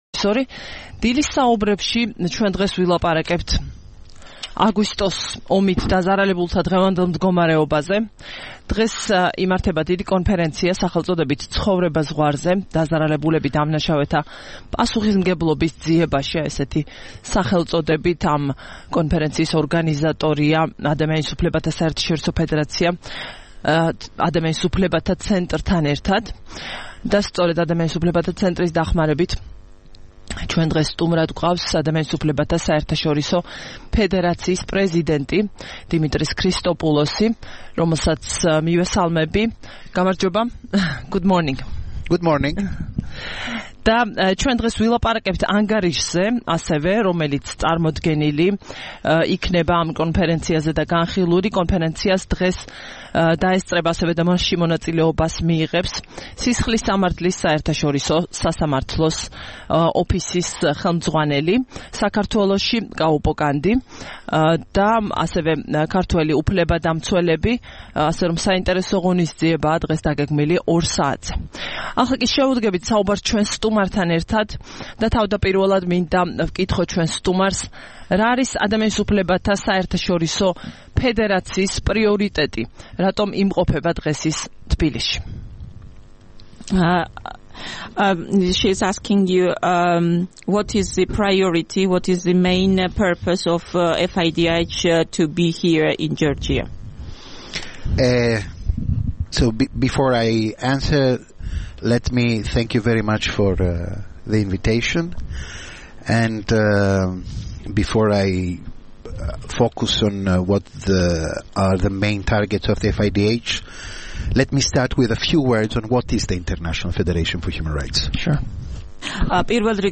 5 თებერვალს რადიო თავისუფლების "დილის საუბრების" სტუმარი იყო დიმიტრის ქრისტოპულოსი, ადამიანის უფლებათა საერთაშორისო ფედერაციის (FIDH) პრეზიდენტი.